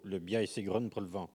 Elle provient de Saint-Gervais.
Catégorie Locution ( parler, expression, langue,... )